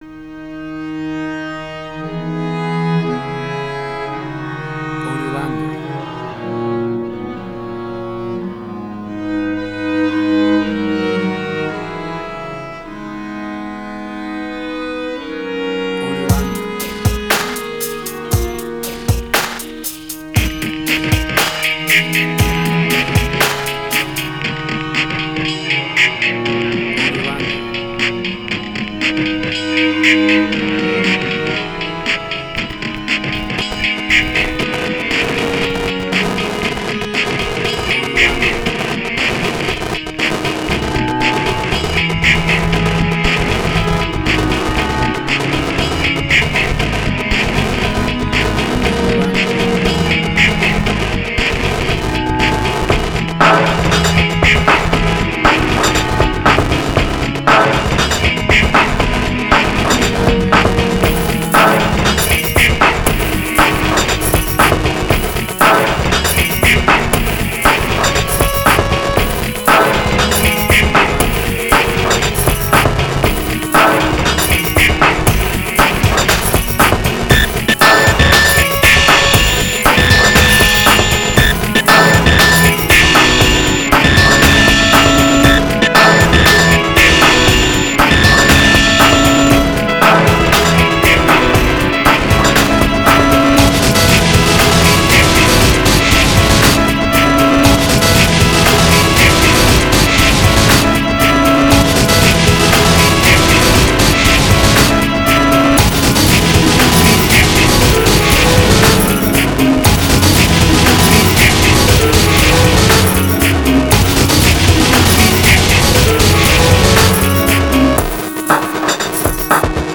IDM, Glitch.
Tempo (BPM): 120